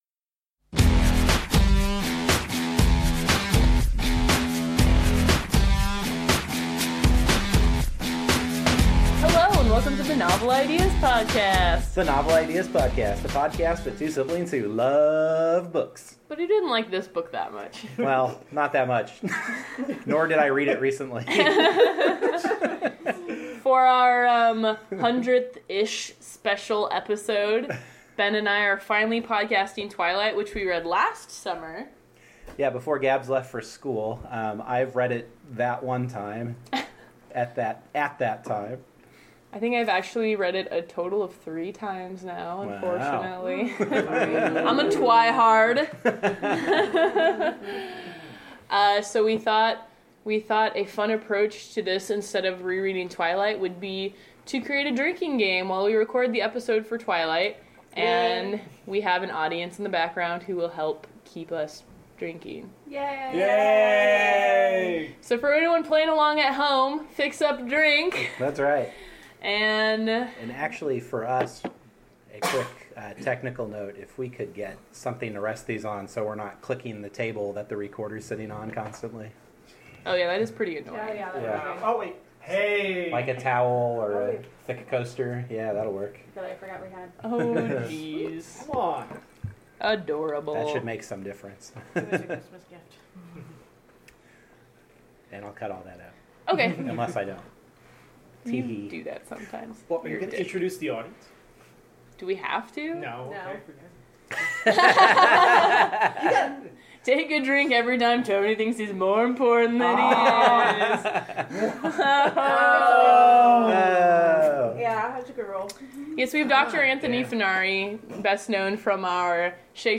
We recorded ourselves discussing Twilight by Stephanie Meyer in the form of a drinking game.
It’s pretty fun and we’re not that much less coherent than usual. In this episode we talk about: literary tourism, free will, stalker-ish behavior, and hear a pretty surprising take on Bella v. Katniss as strong characters.